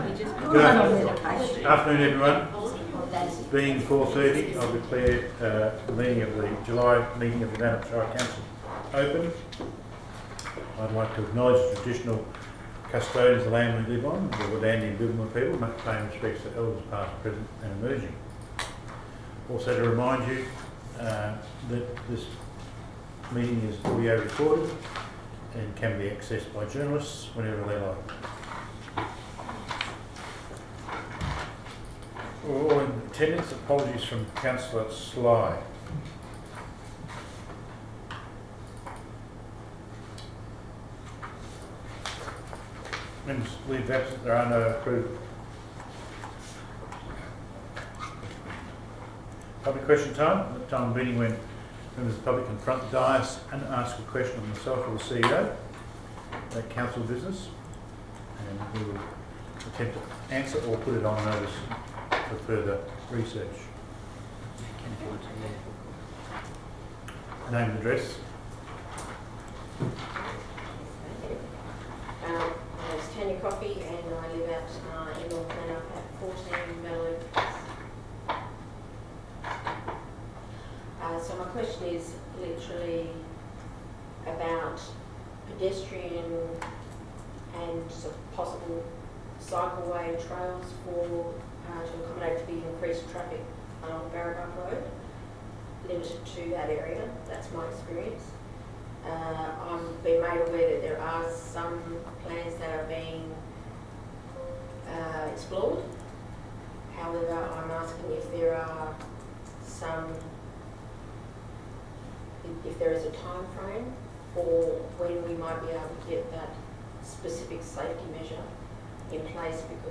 Experience Nannup - Nannup Oral Histories Project